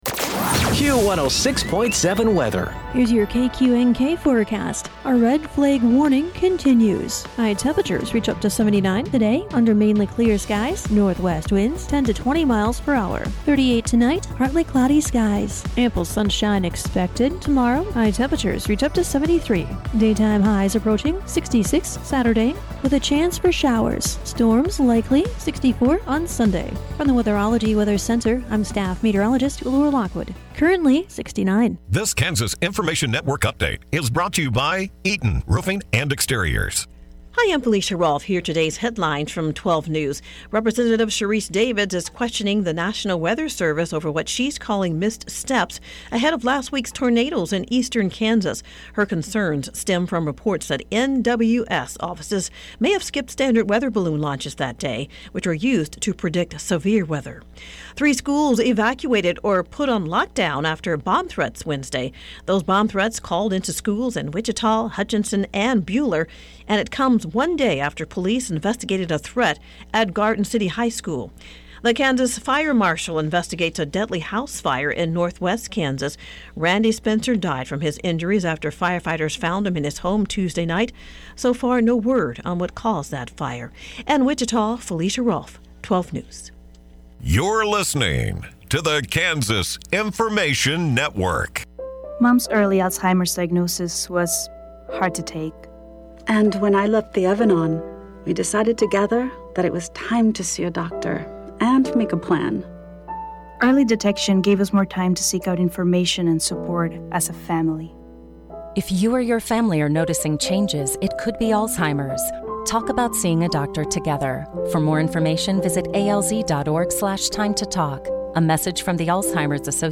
KQNK News - 4/23/2026